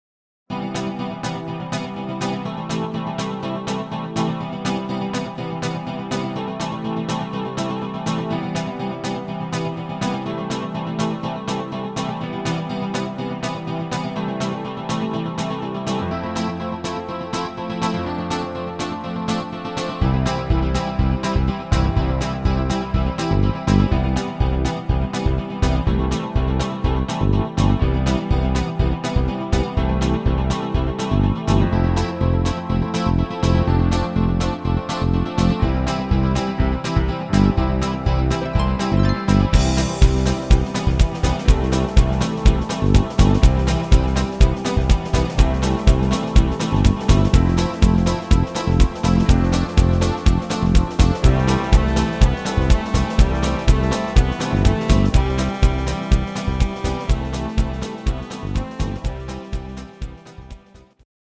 Rhythmus  Swing